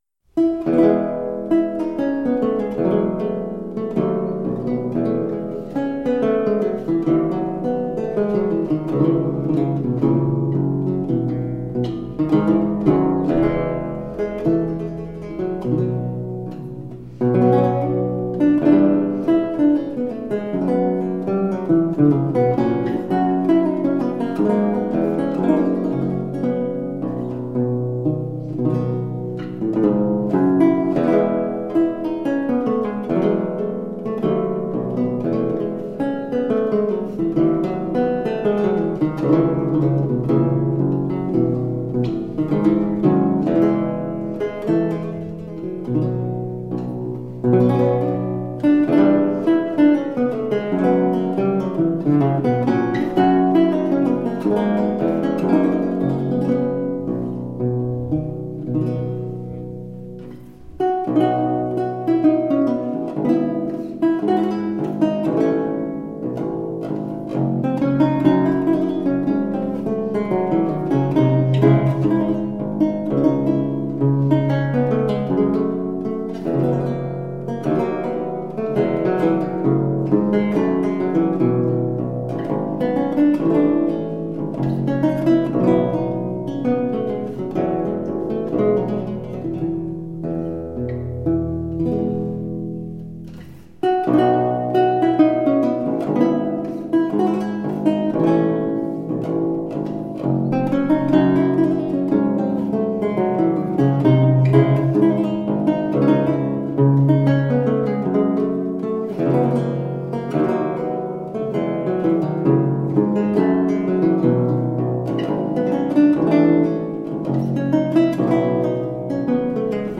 Delicate 17th century chansons.
the three women
with all-original instrumentation
with the intimate feeling of a parlor room recital.